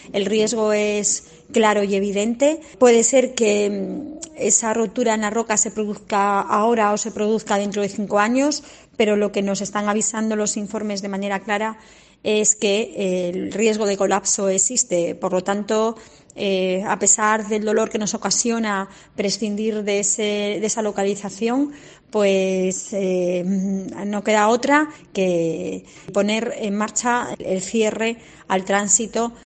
Declaraciones de la alcaldesa de Castrillón, Yasmina Triguero